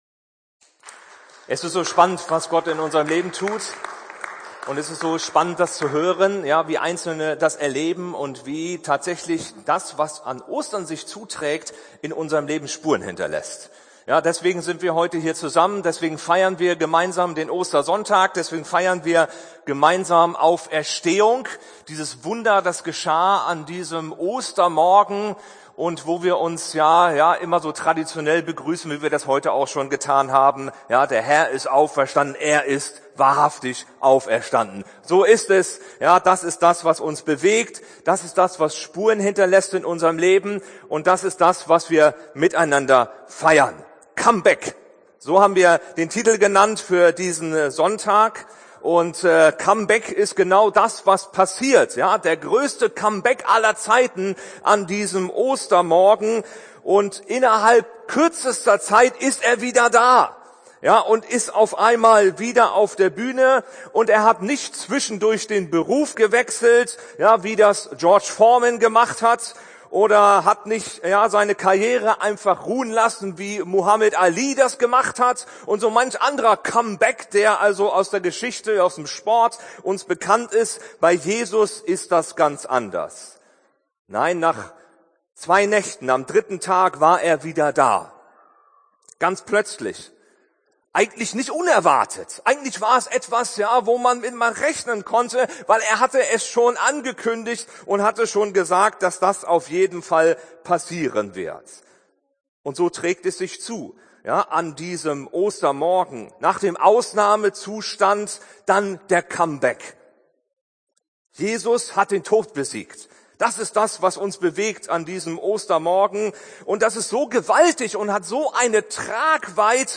Predigt zu Ostern